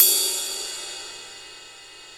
Index of /90_sSampleCDs/Roland L-CD701/CYM_Rides 1/CYM_Ride menu
CYM 22  RD2.wav